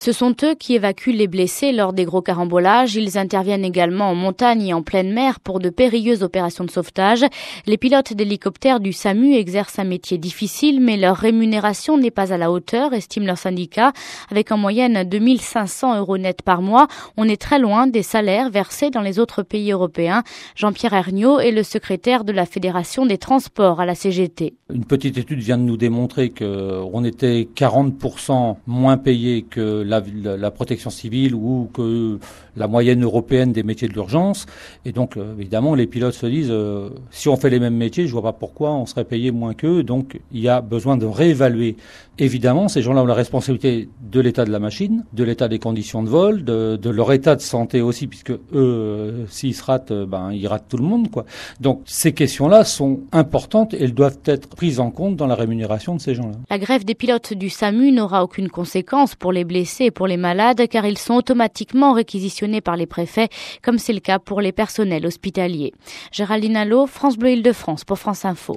Parents pauvres du secours aéroporté Reportage